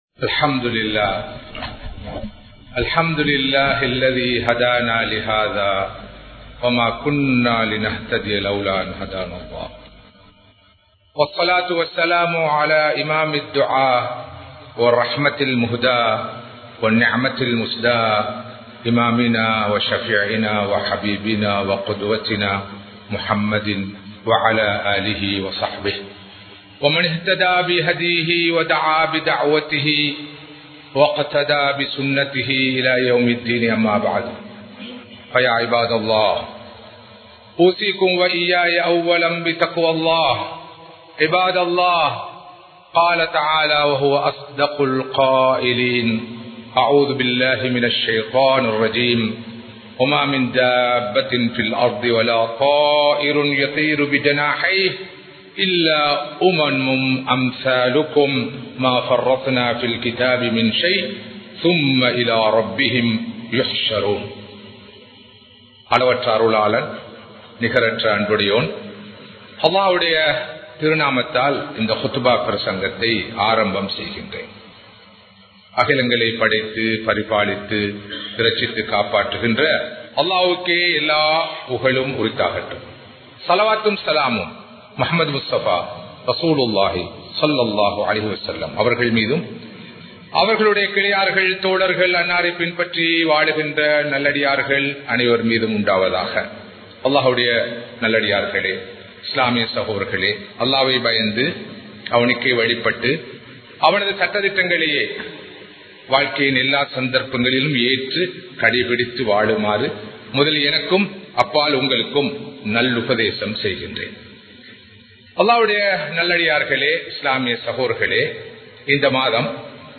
கால்நடைகள் பற்றி இஸ்லாமிய பார்வை | Audio Bayans | All Ceylon Muslim Youth Community | Addalaichenai